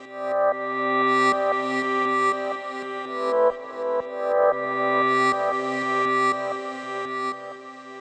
Атмосферный звук (Electro Dub Pad): Lost
Тут вы можете прослушать онлайн и скачать бесплатно аудио запись из категории «Electro Dub».